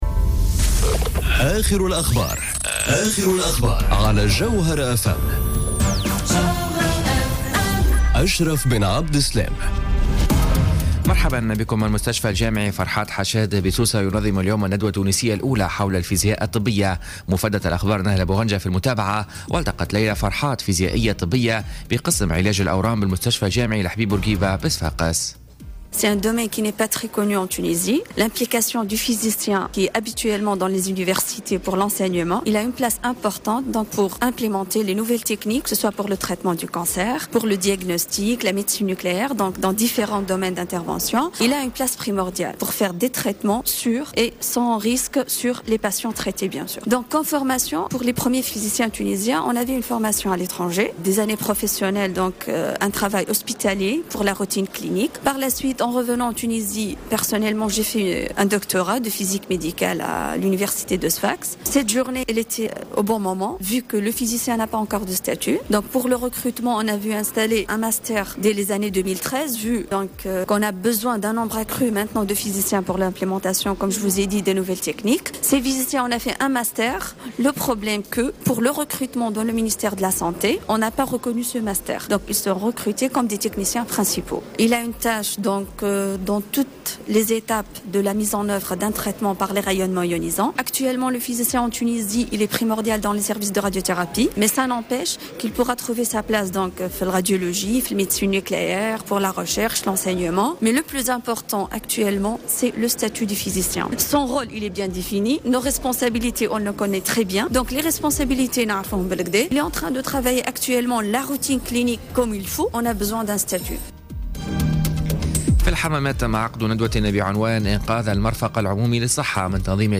نشرة أخبار منصف النهار ليوم السبت 11 نوفمبر 2017